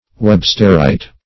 Search Result for " websterite" : The Collaborative International Dictionary of English v.0.48: Websterite \Web"ster*ite\, n. [So named after Webster, the geologist.]